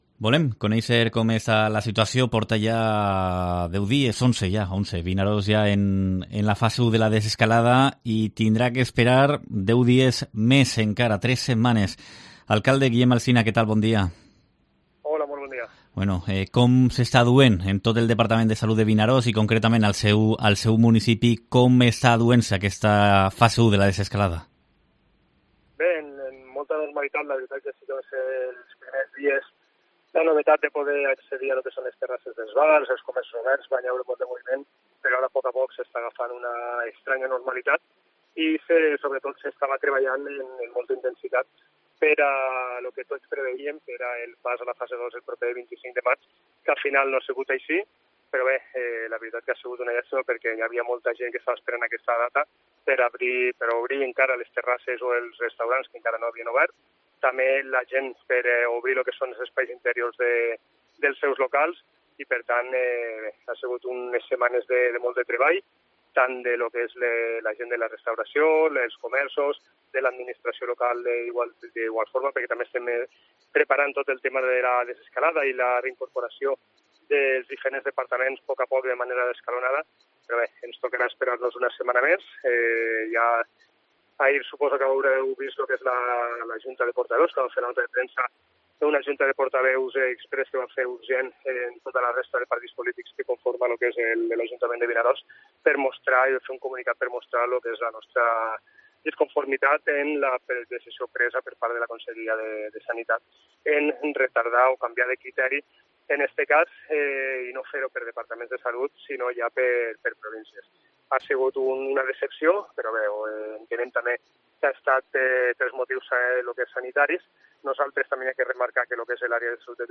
El alcalde de Vinaròs, Guillem Alsina, confía en abrir las playas al baño el 1 de junio